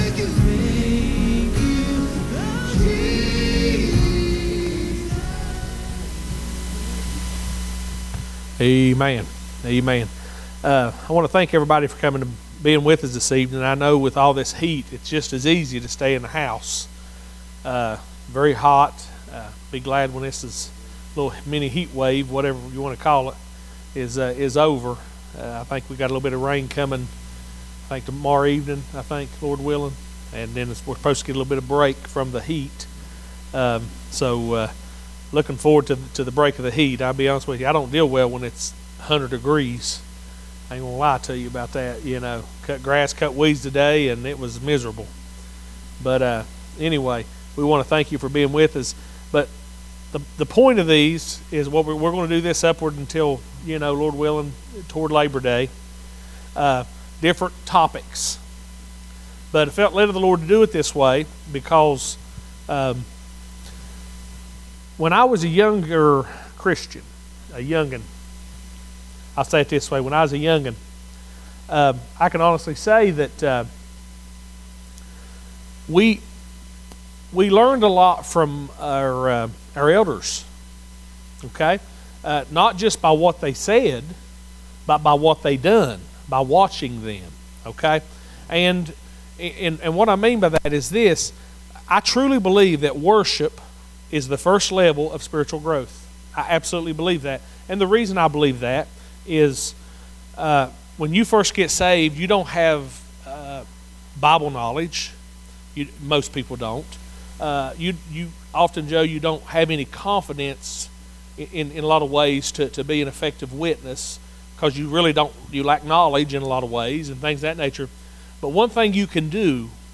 Wednesday Summer Empowerment Sessions